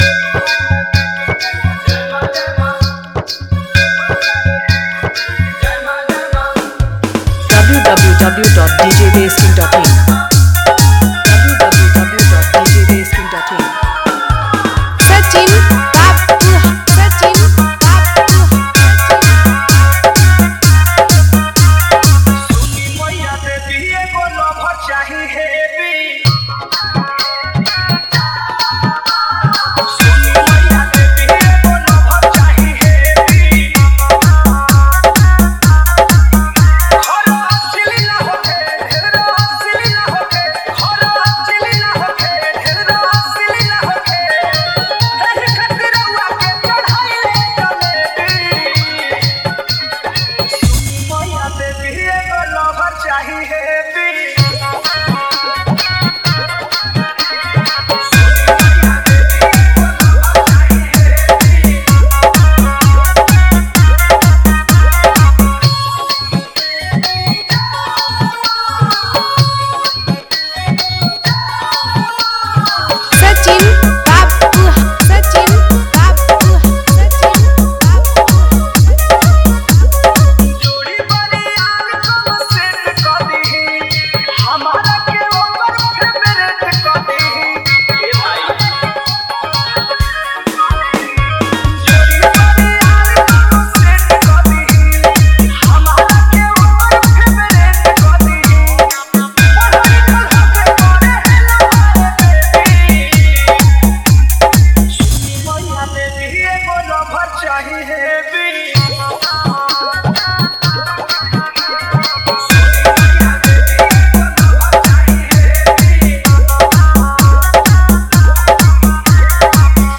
Navratri Dj Remix Songs